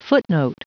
Prononciation du mot footnote en anglais (fichier audio)
Prononciation du mot : footnote